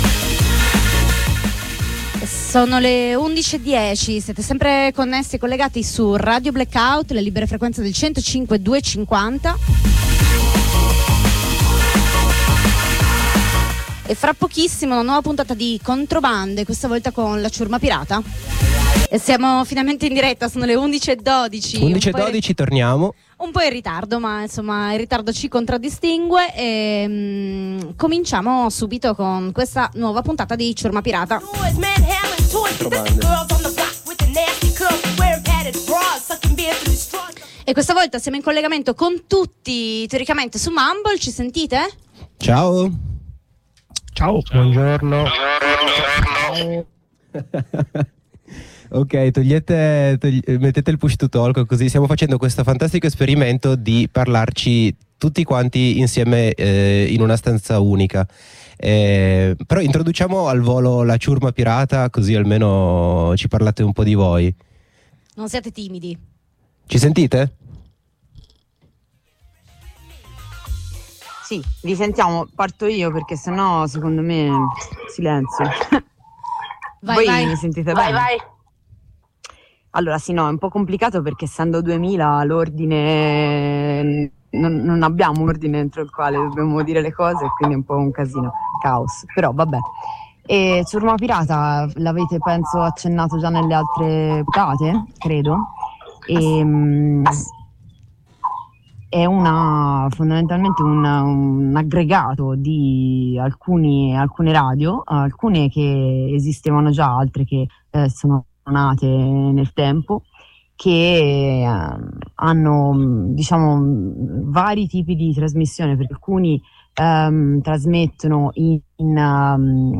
In diretta La Ciurma Pirata che fa rete assieme per trasmettere in AM e Streaming da Roma (radio Forte) Bologna (radio spore) Firenze (radio wombat) e Genova (Radio Gramma)
jingle falsi e veri del gr